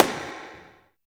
39 AMB SNR-L.wav